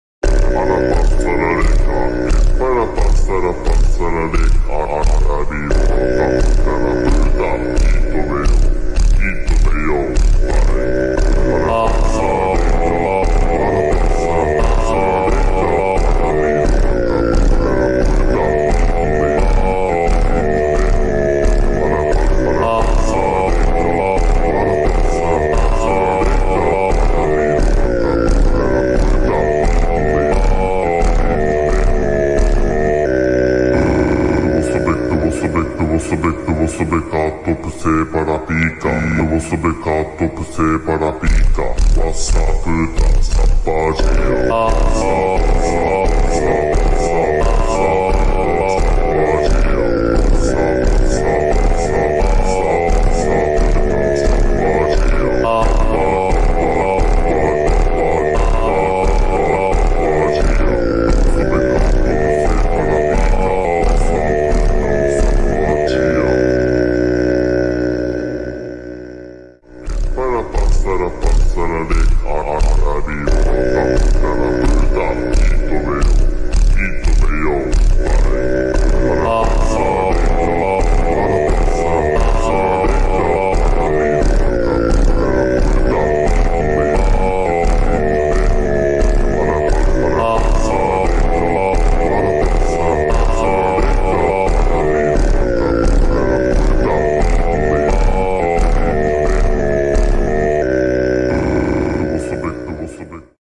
در ورژن slowed
فانک